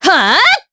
Aselica-Vox_Attack3_jp.wav